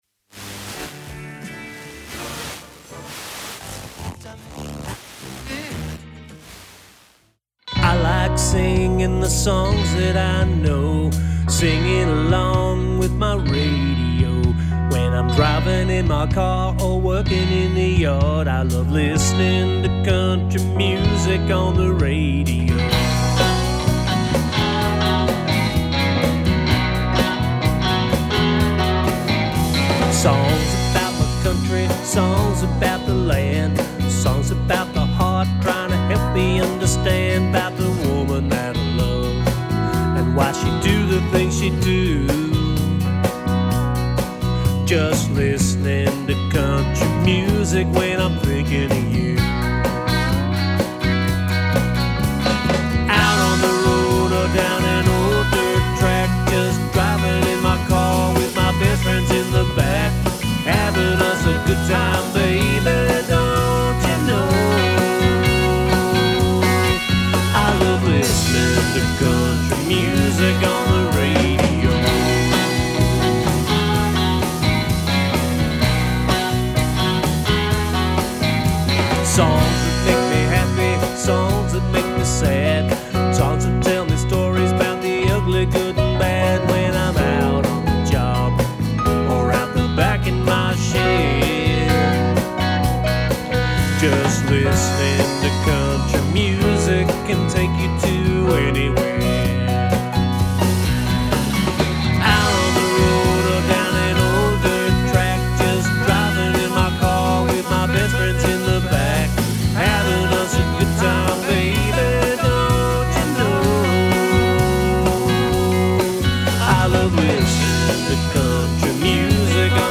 an Australian country music singer
classic country sound